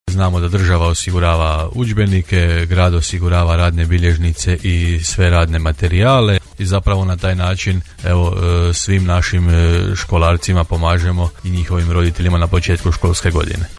Gradonačelnik Hrvoje Janči istaknuo je kako je ovo jedanaesta godina kako Grad Đurđevac sufinancira nabavu udžbenika za srednjoškolce i radnih bilježnica, te nabavu radnih bilježnica i materijala za osnovnoškolce uključujući i učenike Područne škole Suha Katalena čiji osnivač nije Grad Đurđevac;